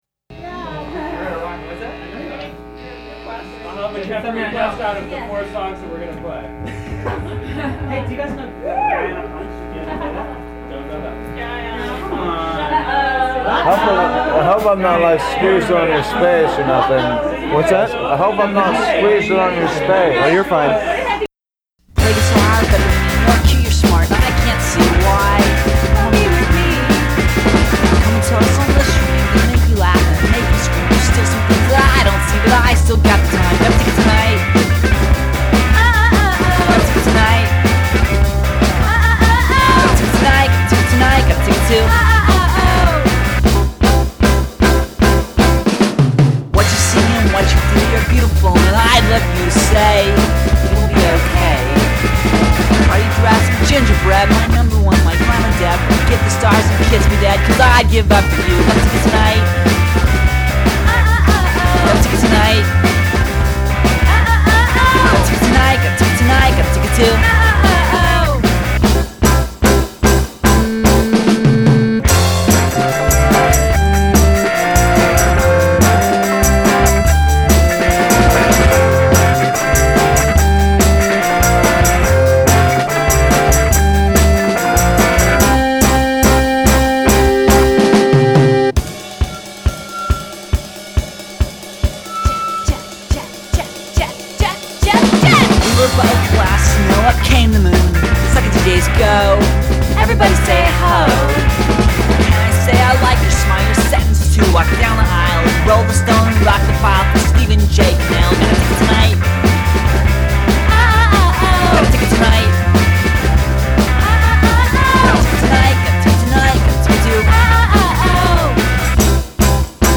twee pop